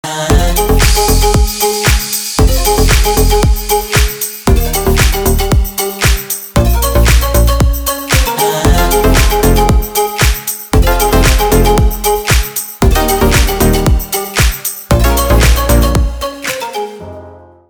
• Качество: 320, Stereo
поп
заводные
dance
без слов
красивая мелодия